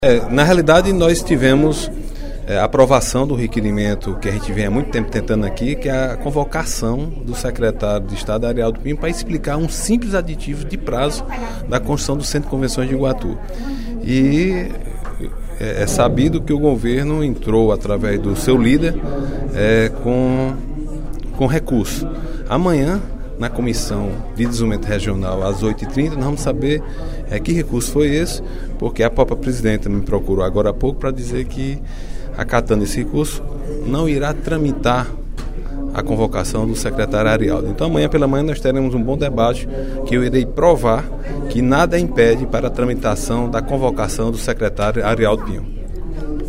O deputado Agenor Neto (PMDB) destacou, no primeiro expediente da sessão plenária desta terça-feira (31/05), a aprovação do requerimento de sua autoria, na Comissão de Desenvolvimento Regional, Recursos Hídricos, Minas e Pesca, convocando o secretário estadual de Turismo, Arialdo Pinho, para vir à Assembleia Legislativa. Ele deverá prestar explicações sobre a decisão de não assinar a renovação do convênio de construção do Centro de Eventos do município de Iguatu.